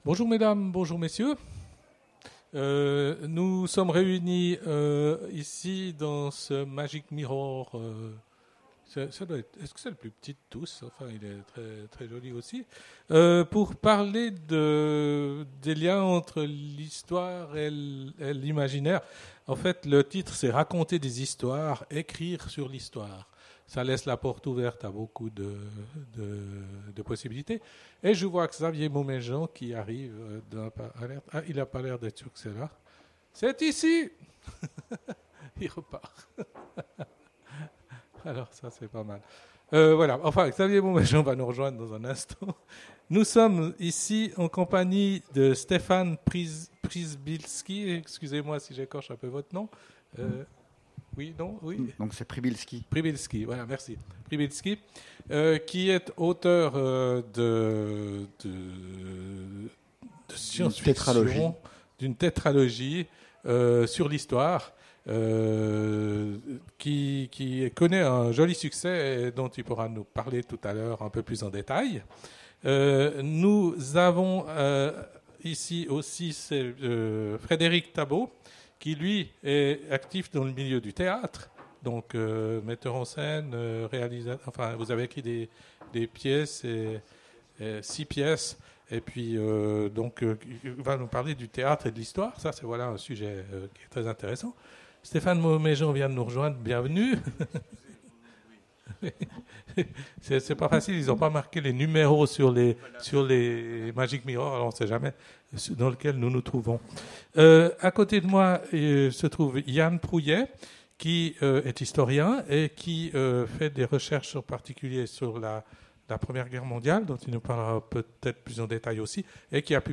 Imaginales 2017 : Conférence Raconter des histoires… écrire sur l'histoire !